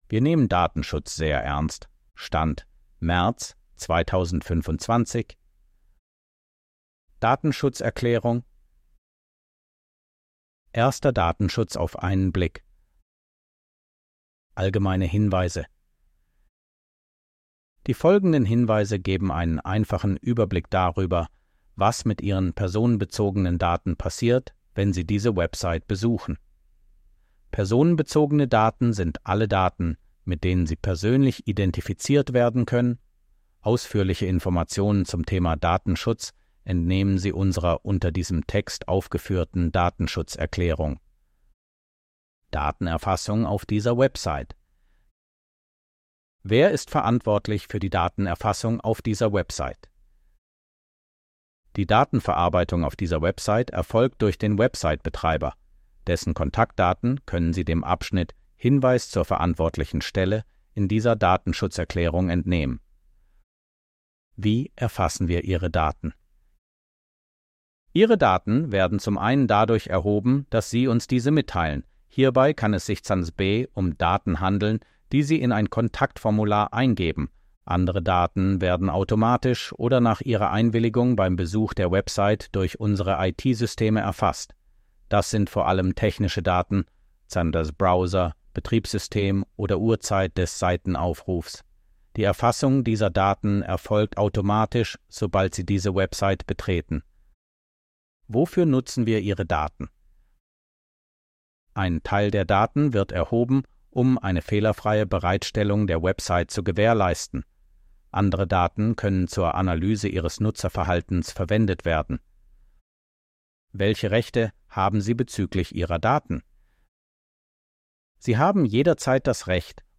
Audio-Datei mit vorgelesenem Text Barrierefreiheitserklärung der Gärtnerei Hohn.